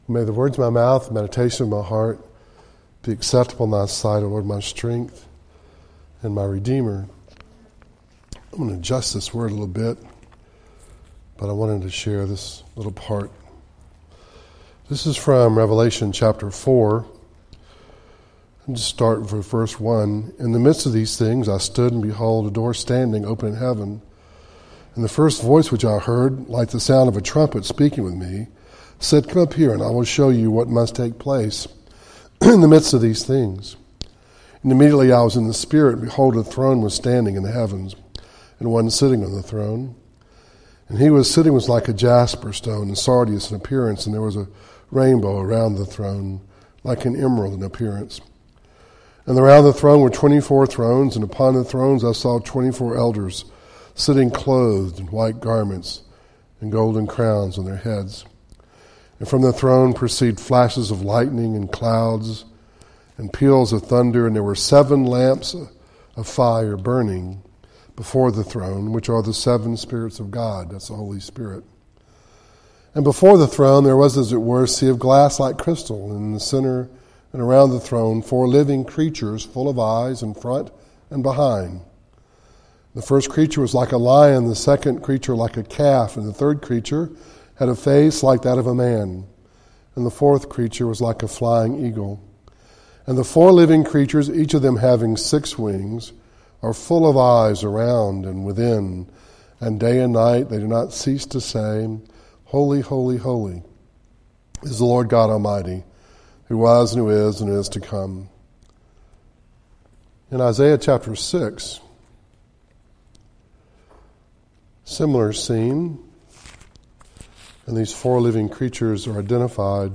Psalms 119:1-30 Service Type: Devotional